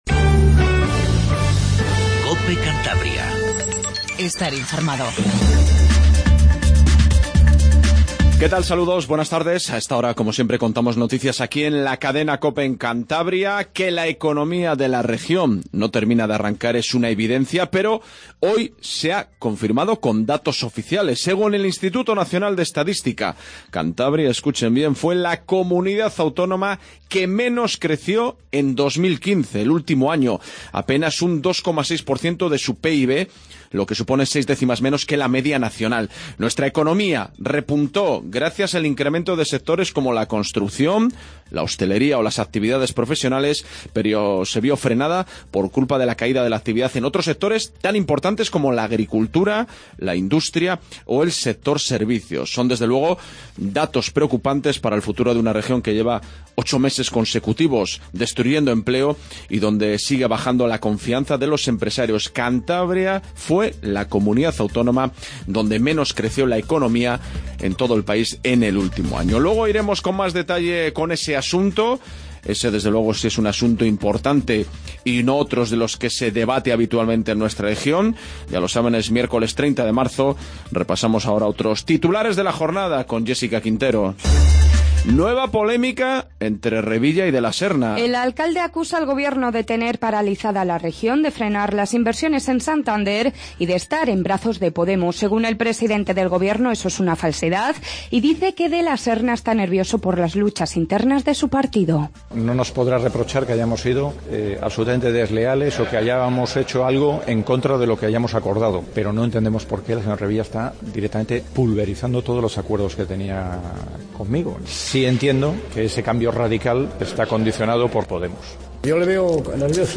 INFORMATIVO REGIONAL 14:10